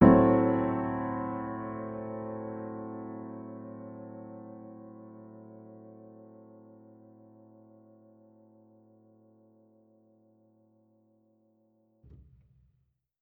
Index of /musicradar/jazz-keys-samples/Chord Hits/Acoustic Piano 2
JK_AcPiano2_Chord-Am6.wav